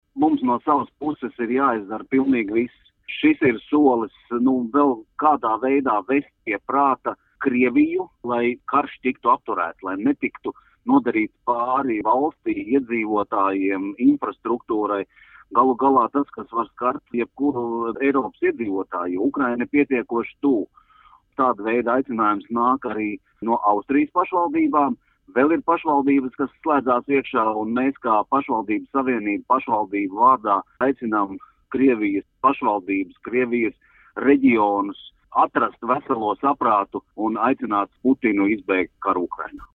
Par iniciatīvu lūdzām pastāstīt Latvijas Pašvaldību savienības priekšsēdi Gintu Kaminski  :